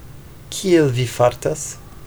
Kiel vi fartas? [ˈki.el vi ˈfar.tas]